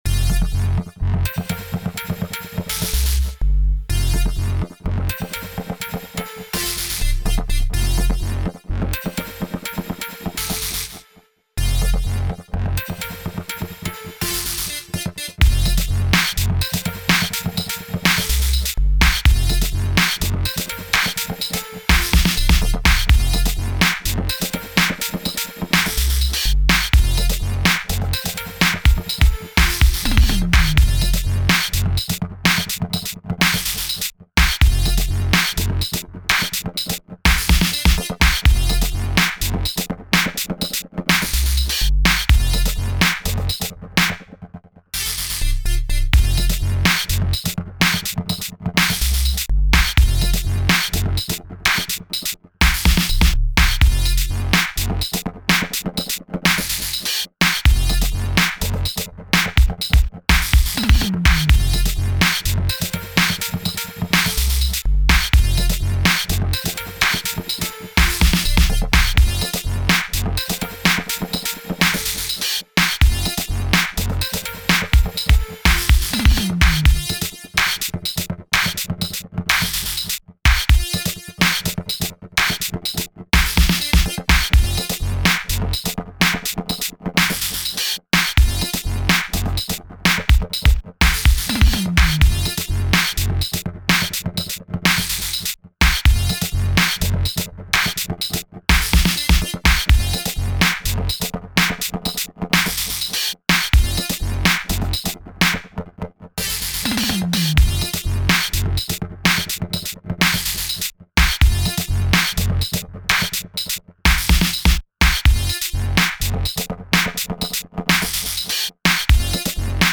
Reggae
Description: Dancehall riddim